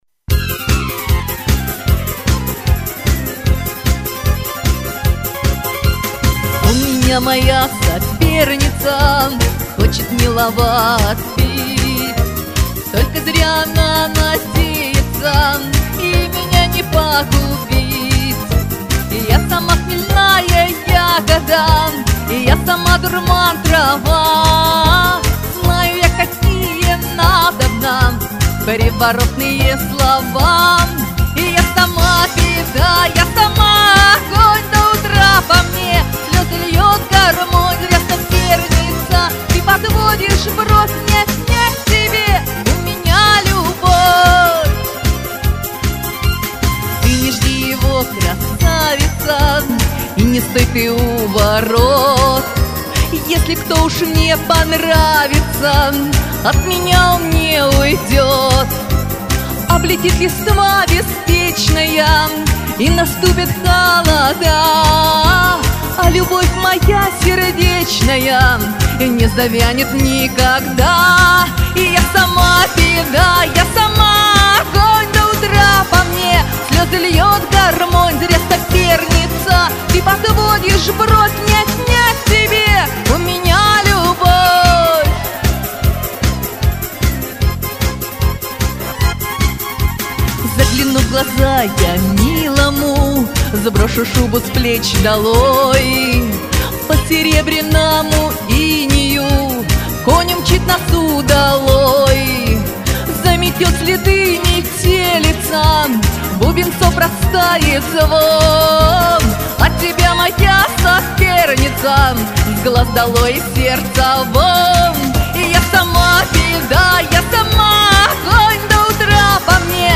К обеих девушек голос весьма подходит к народной песне.